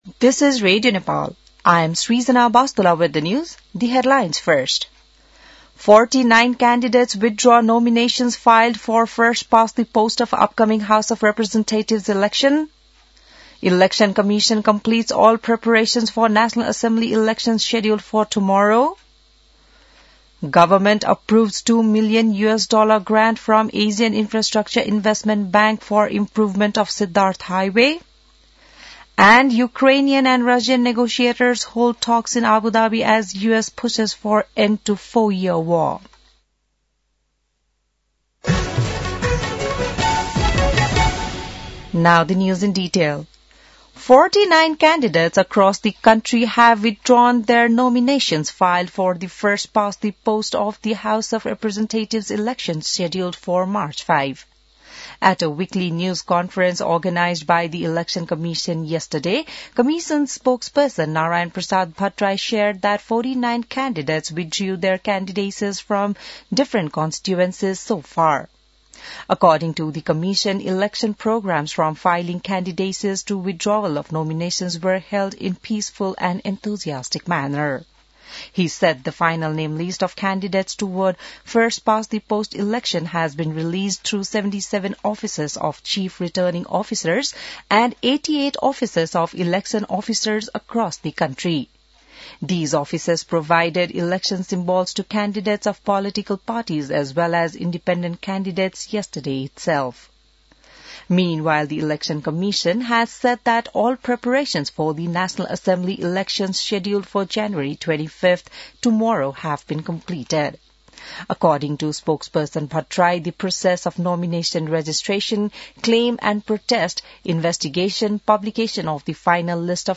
बिहान ८ बजेको अङ्ग्रेजी समाचार : १० माघ , २०८२